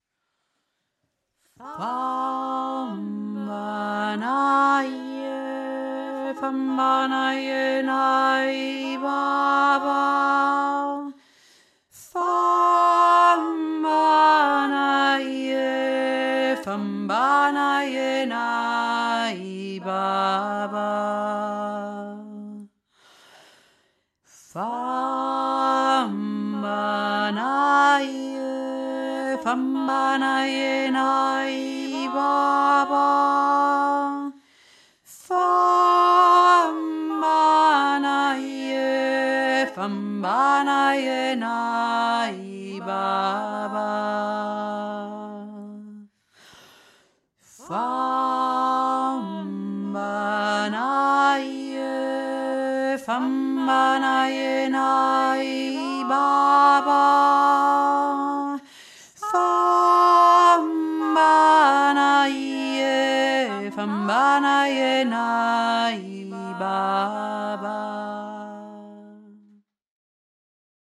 tiefe Stimmme
famba-naje-tiefe-stimme.mp3